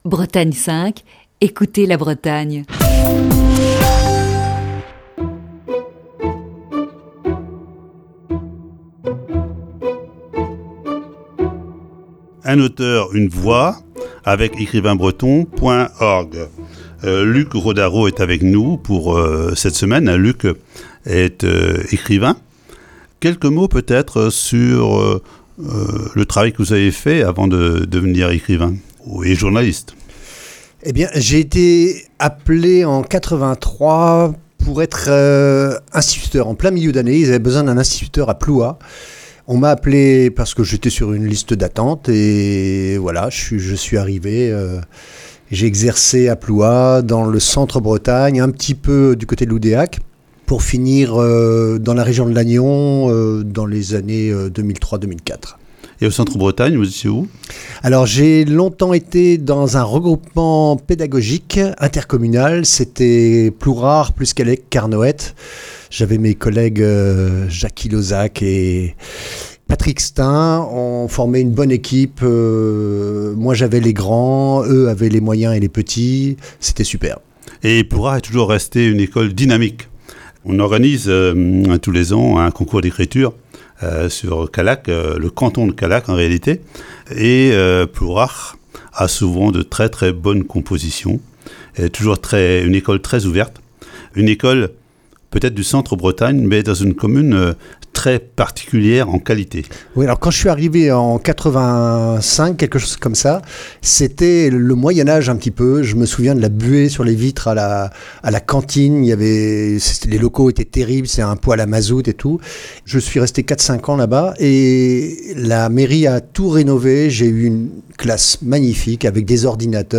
Voici ce lundi, la première partie de cette série d'entretiens.